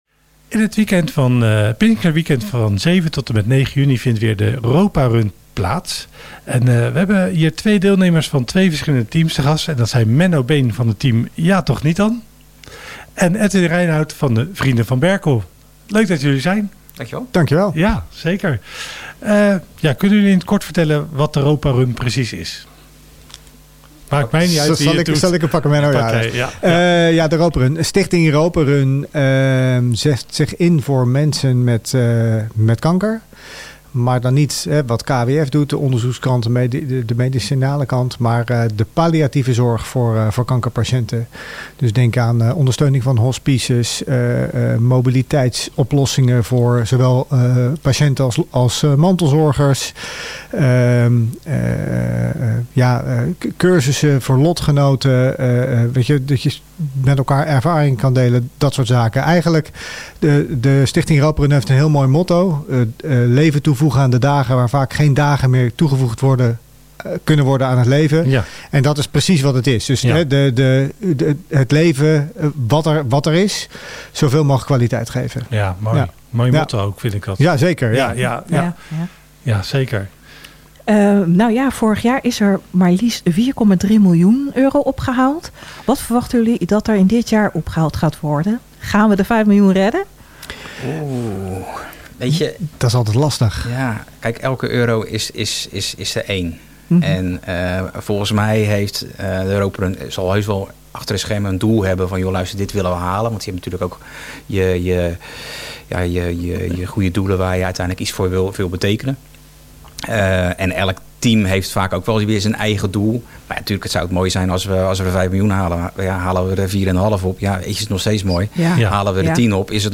vertelden in de radiostudio van RTV Lansingerland over hun deelname en de betekenis van dit bijzondere evenement.